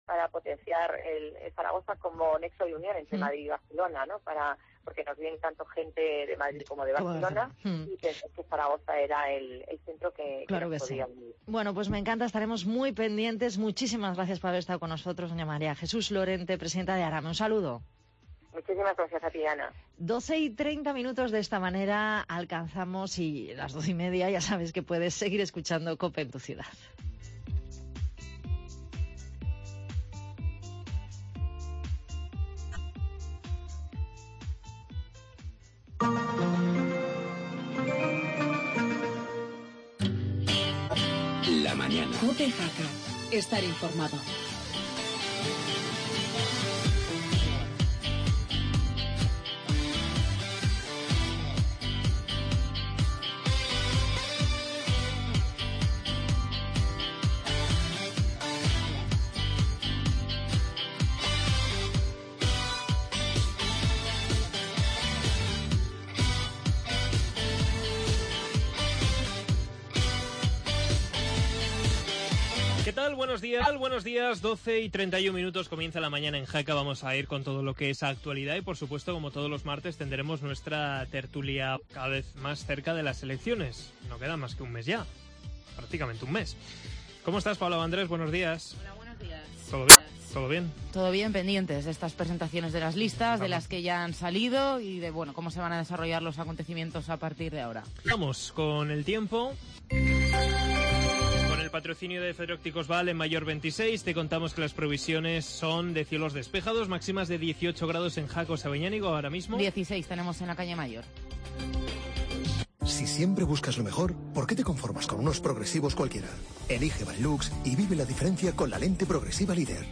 AUDIO: Tertulia municipal de Jaca